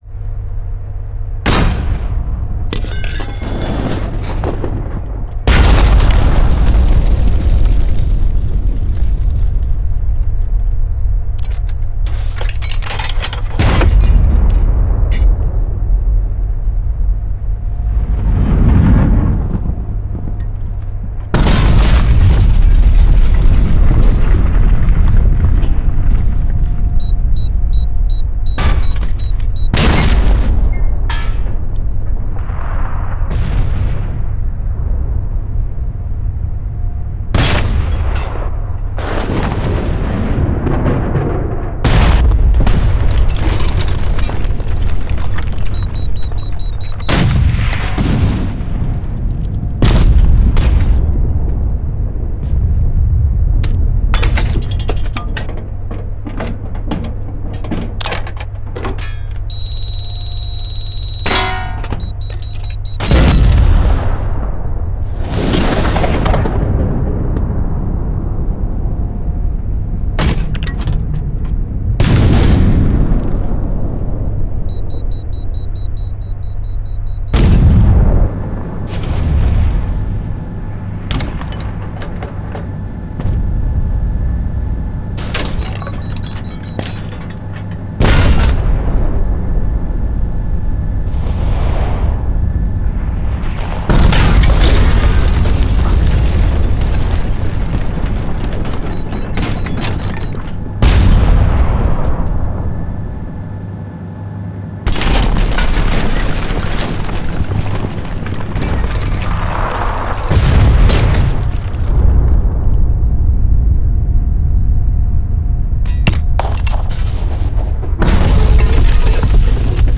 Artillery - Designed Howitzer and Explosion Sound Effects
Download powerful cannon blasts, explosive sounds, and destructive effects for games, movies and more.
Bluezone-Artillery-Designed-Howitzer-and-Explosion-Sound-Effects-Demo.mp3